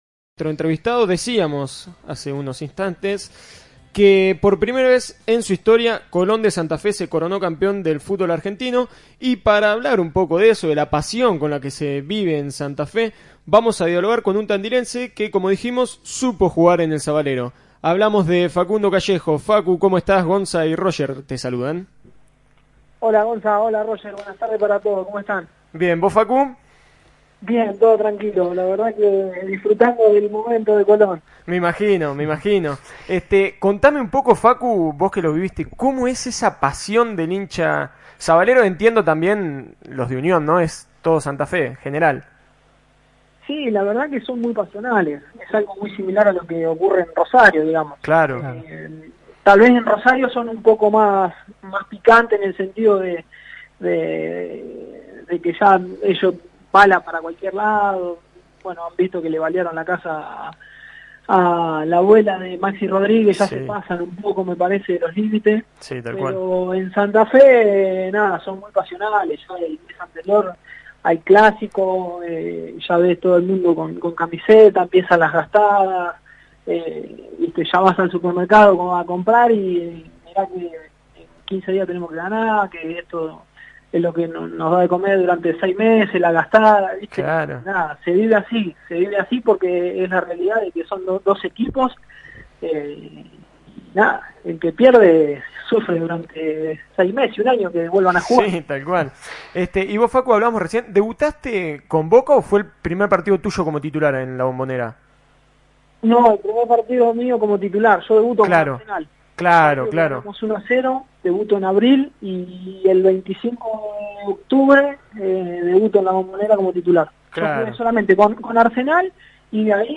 Escuchá la entrevista completa y accedé al Instagram del programa para conocer mucho más.